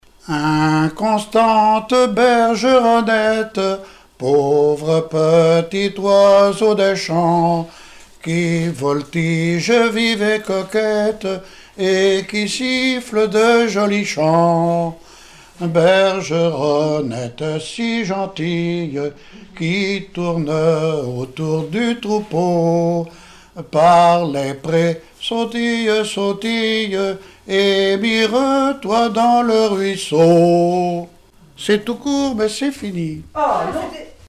Tallud-Sainte-Gemme
Témoignages et chansons
Pièce musicale inédite